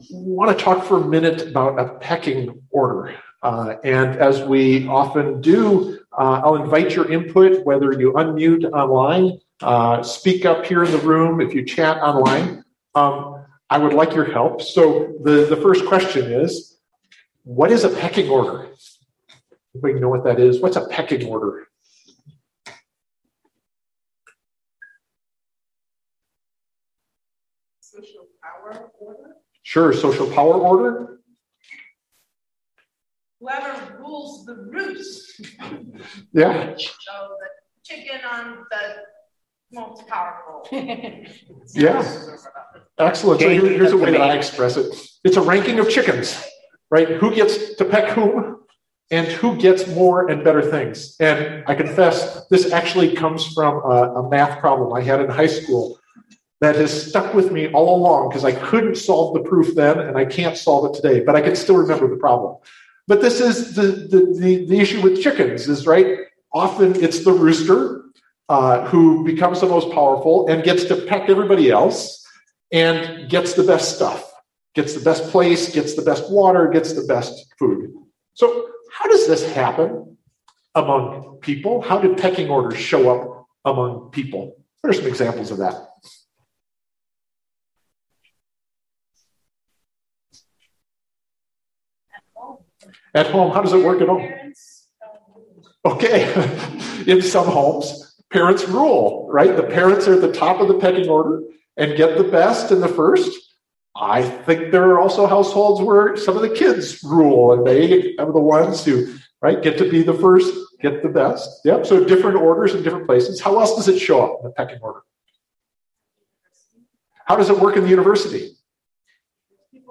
CMC Sermon